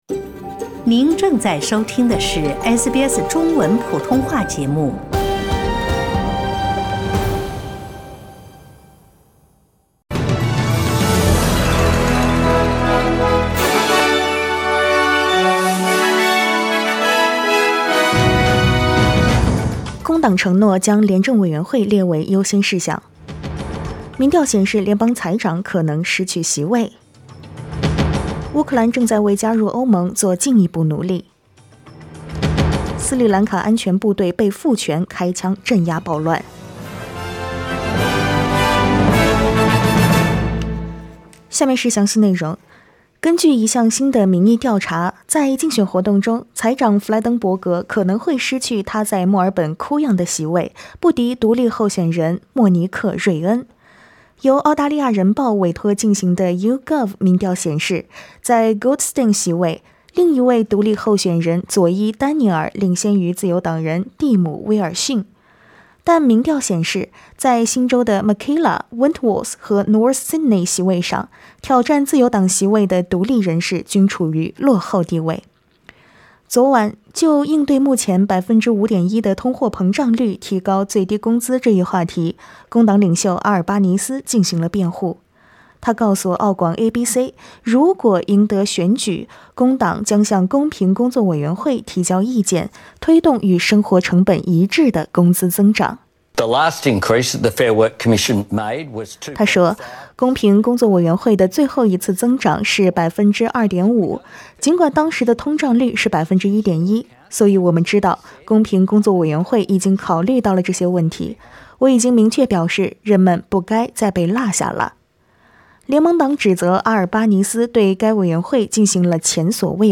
SBS早新闻（5月11日）
SBS Mandarin morning news Source: Getty Images